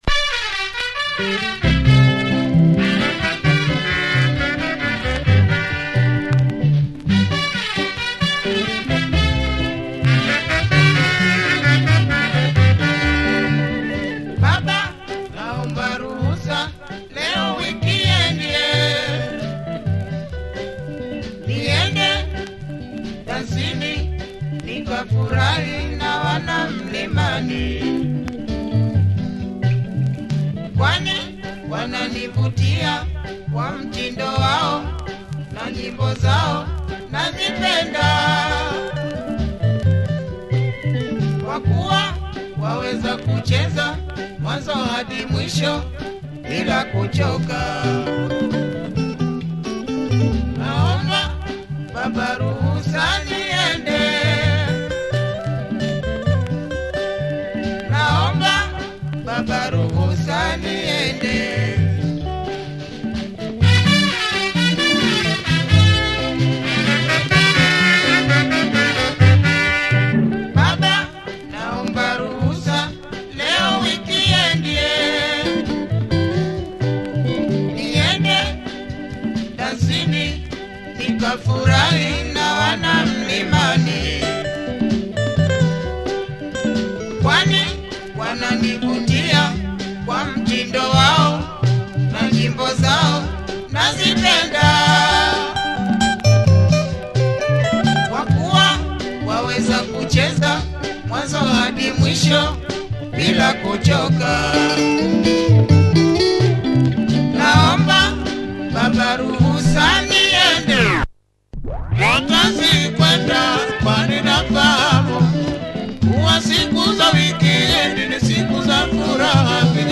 Great tune, super horns!